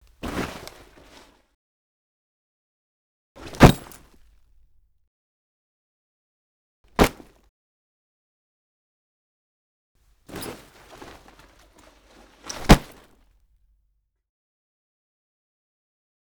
household
Dropping Backpack Or Bag Onto Floor